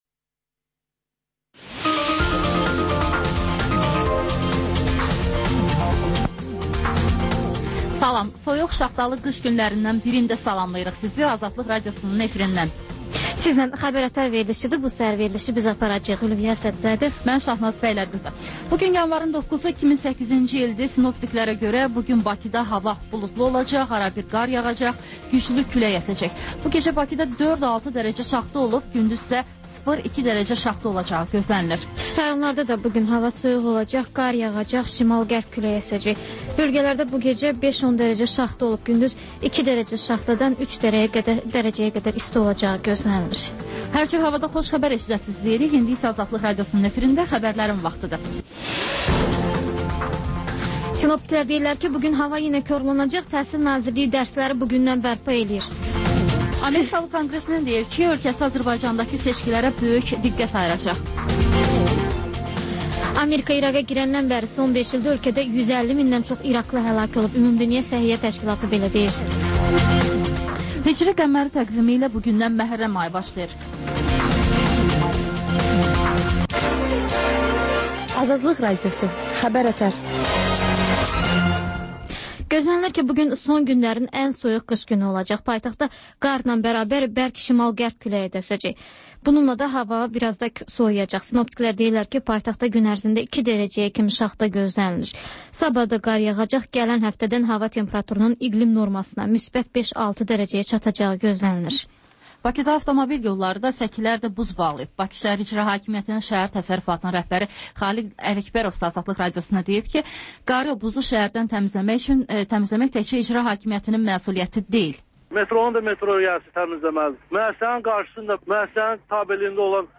Xəbərlər, müsahibələr